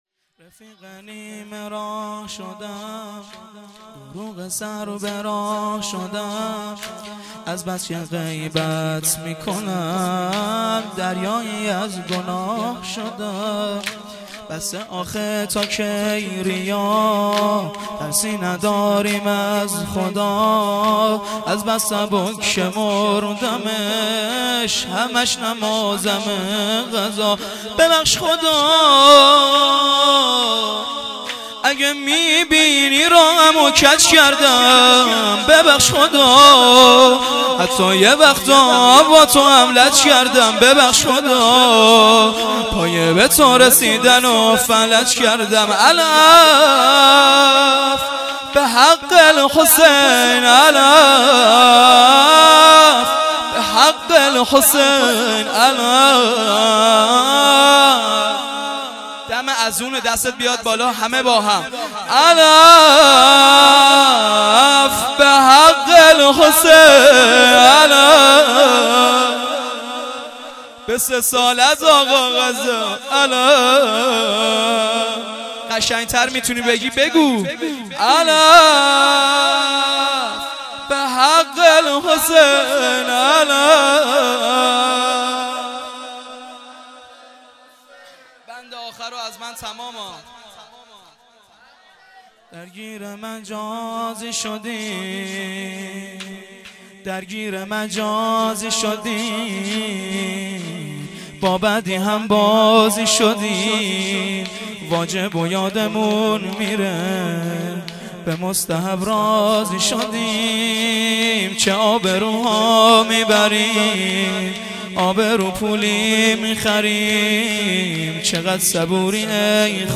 فایل های صوتی مراسم سینه زنی اولین جمعه ماه رمضان
شور[منم باید برم